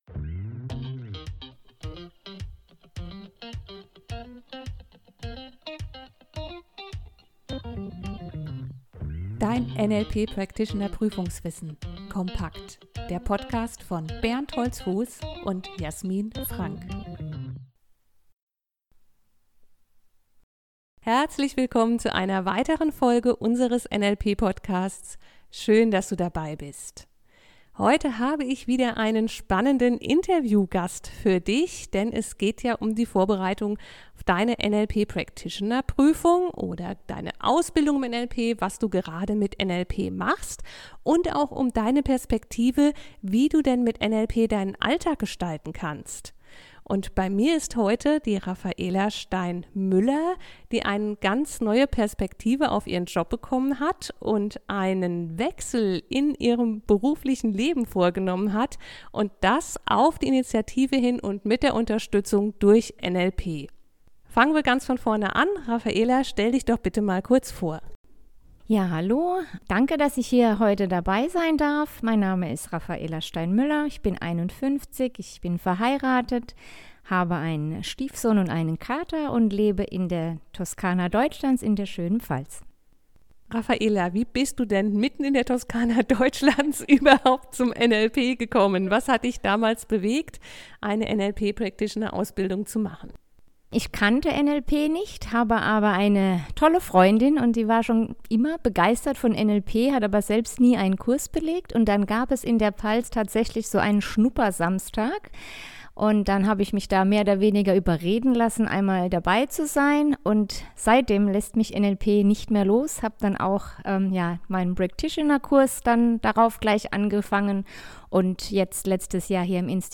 NLP Interview